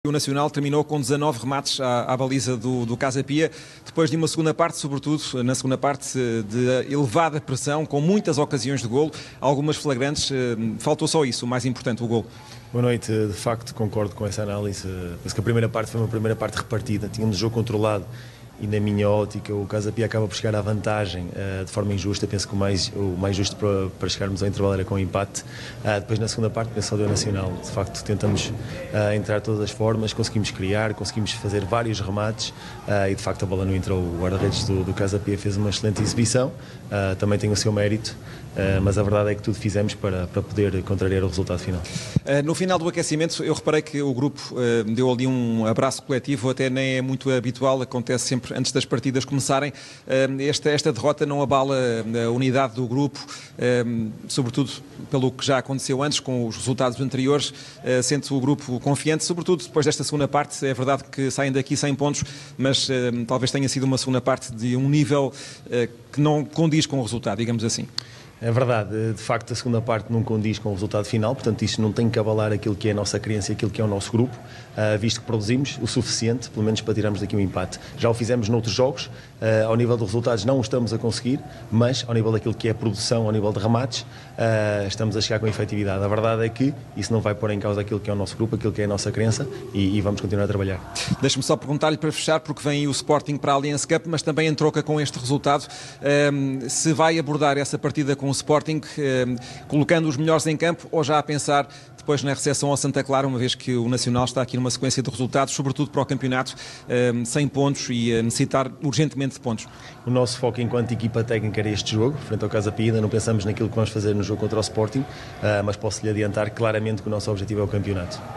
Na flash-interview realizada pela Sport Tv no final do encontro com o Casa Pia